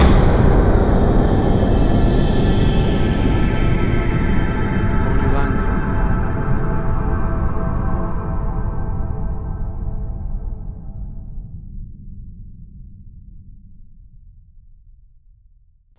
BIG HORROR SYNTH HIT – 0:16″
A dark and scary Horror Hit! Perfect for Horror Trailers for hitting your cue points and making a big scary impact!
Big-Horror-Synth-HitCS.mp3